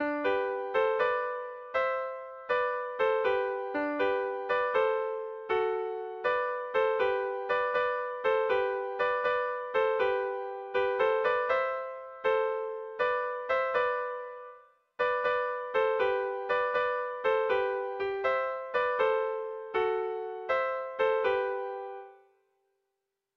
Kontakizunezkoa
ABD1D2